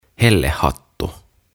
Tuhat sanaa suomeksi - Ääntämisohjeet - Sivu 4